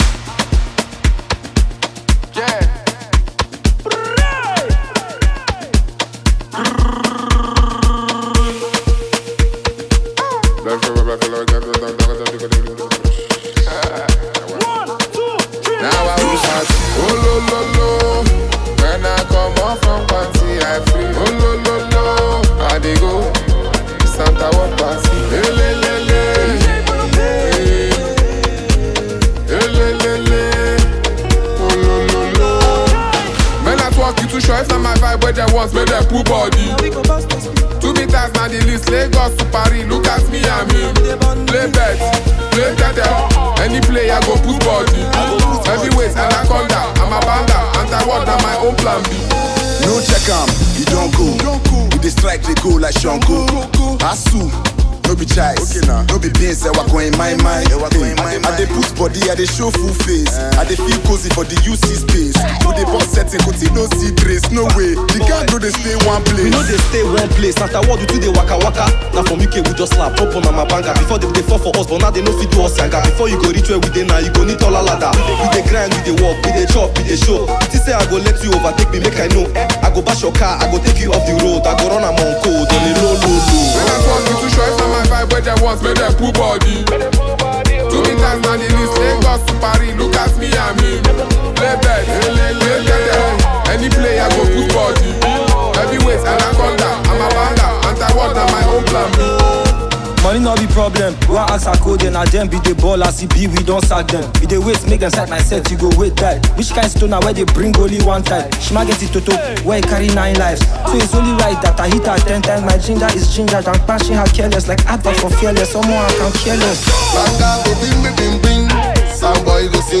Nigeria talented Afrobeats music singer and songwriter